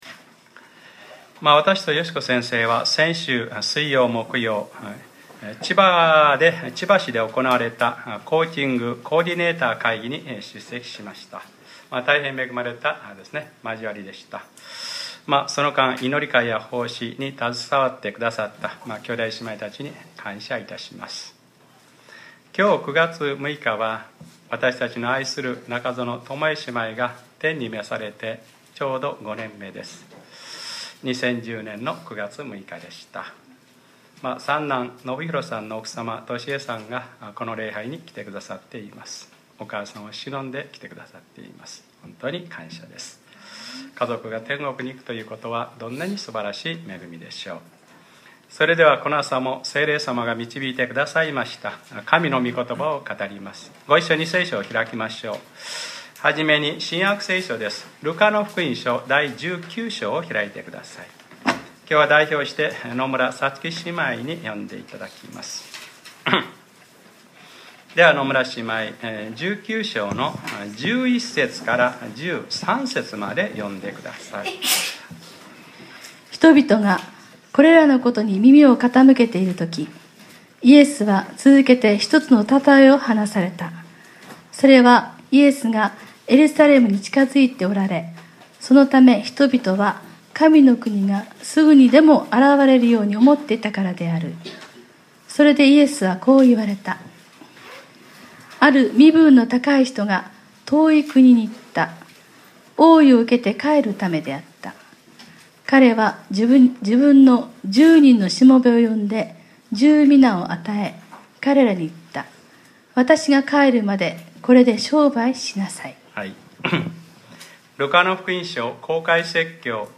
2015年09月06日（日）礼拝説教 『ルカｰ６７：主がお入用なのです』